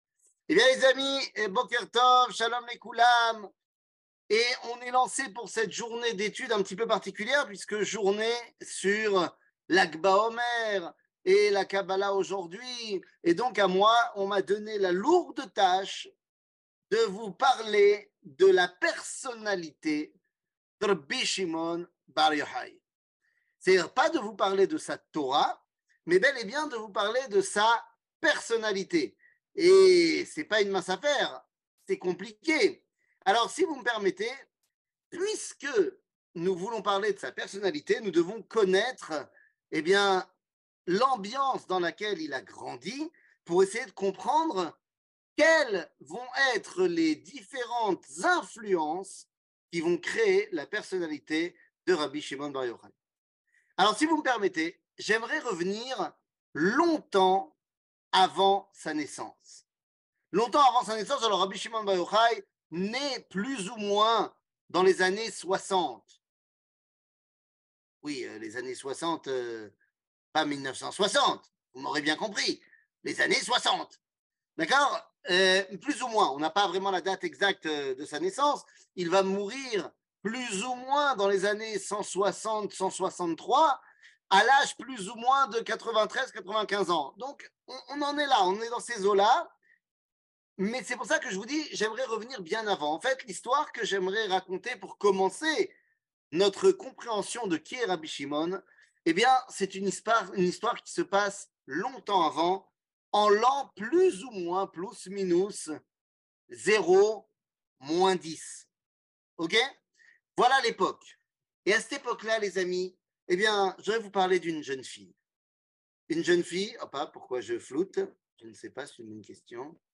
שיעור מ 07 מאי 2023 50MIN הורדה בקובץ אודיו MP3